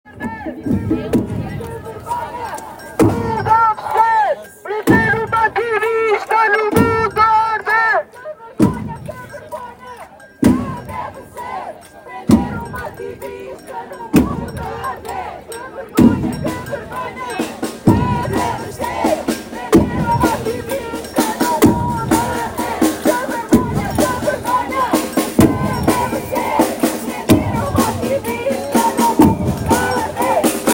Ativistas entoam “Que vergonha, que vergonha que deve ser prender um ativista num mundo a arder” durante a manifestação organizada pelo movimento Climáximo, em novembro de 2024
A marcha fez-se ao som de tambores, palavras de ordem e algumas paragens.